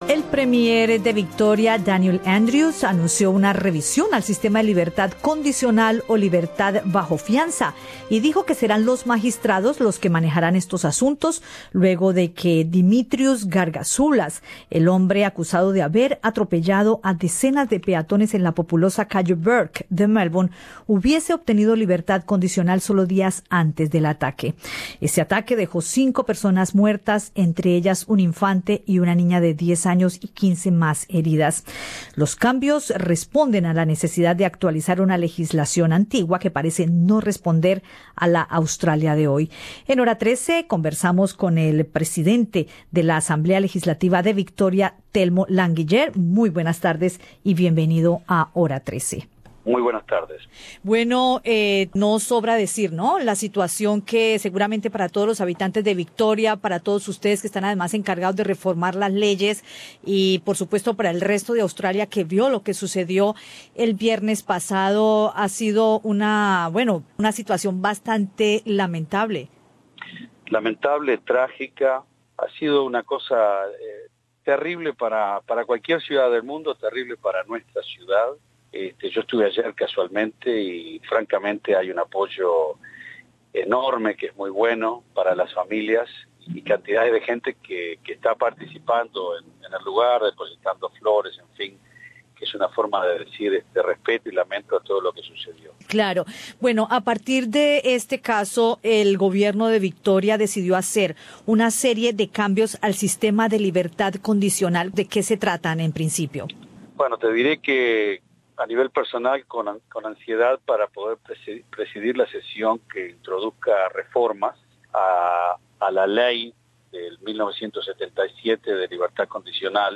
Los cambios responden a la necesidad de actualizar una legislación antigua, que parece no responder a la Australia de hoy, dijo al programa Hora 13, de Radio SBS, el presidente de la Asamblea Legislativa de Victoria, Telmo Languiller.